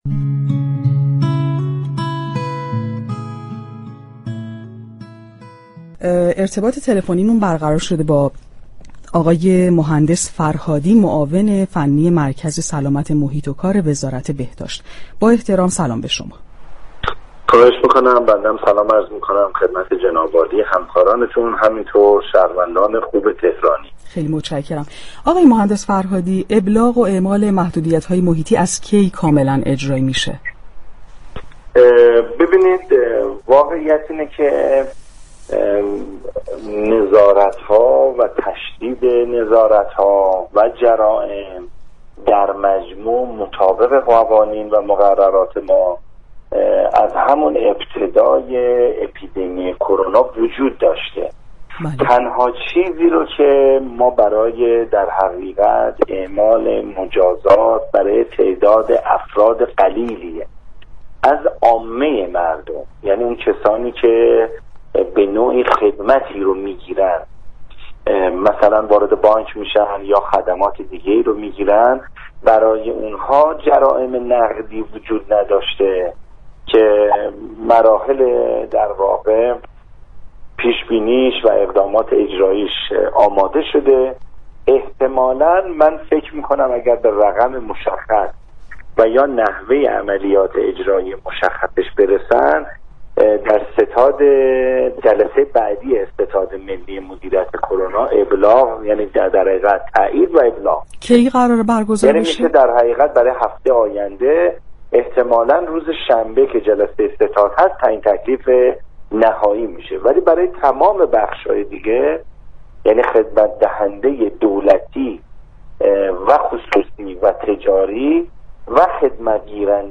محسن فرهادی در برنامه تهران كلینیك رادیو تهران درباره ابلاغ و اعلام محدودیت های محیطی در جلوگیری از شیوع بیشتر كرونا گفت: